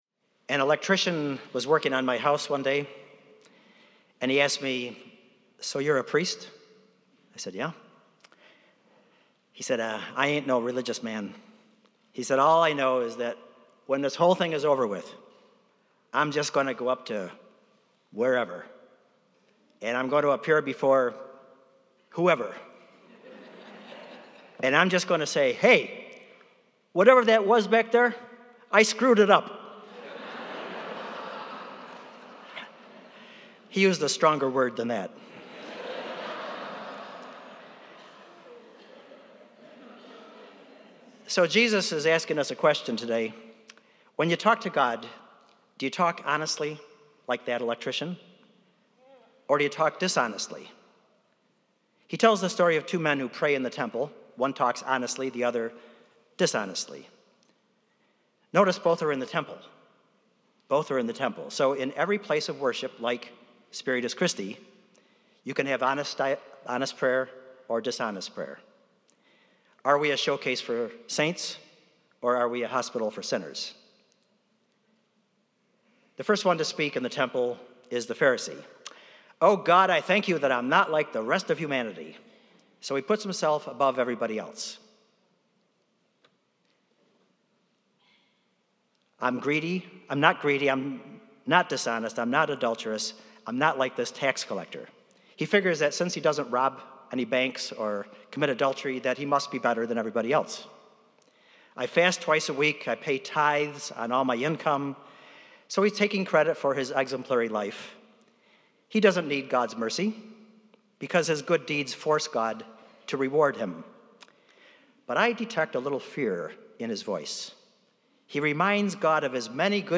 preaches on Jesus’ story of the tax collector and the pharisee at the temple. Although the tax collector has taken advantage of many, he is honest with God and takes responsibility for his actions. The pharisee on the other hand is not honest and pats himself on the back for his “good deeds.”